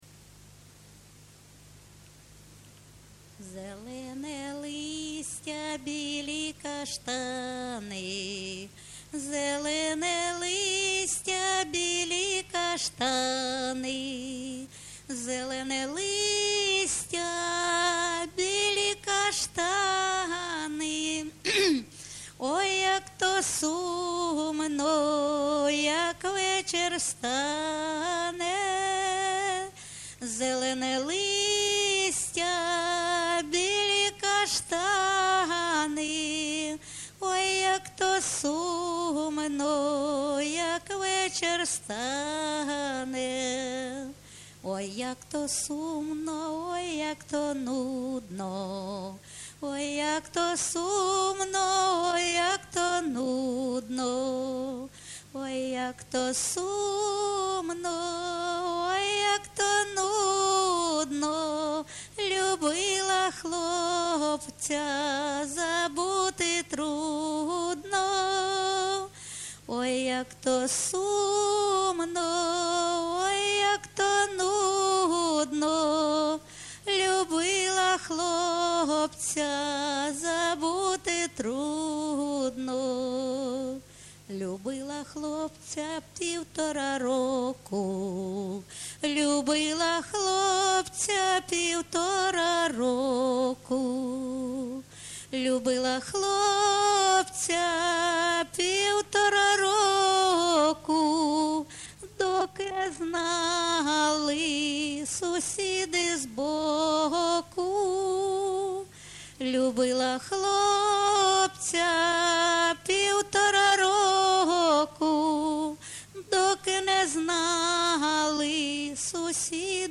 ЖанрСучасні пісні та новотвори
Місце записус-ще Новодонецьке, Краматорський район, Донецька обл., Україна, Слобожанщина